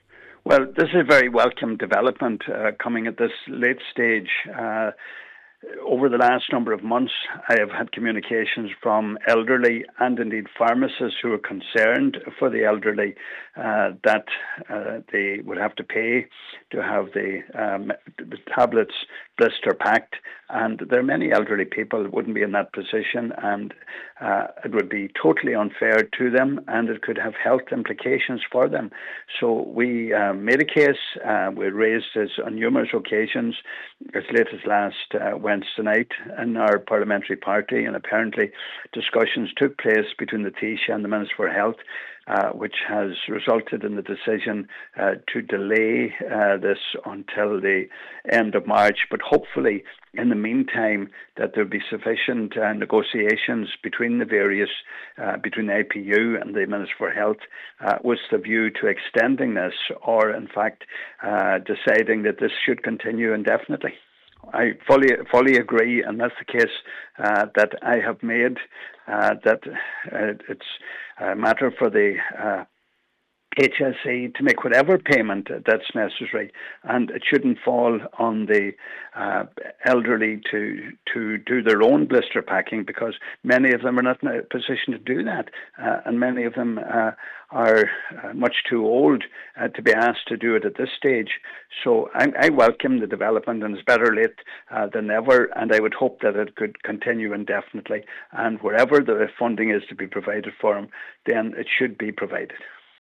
Donegal Deputy Pat the Cope Gallagher says it is not fair that the charges must fall upon the most vulnerable: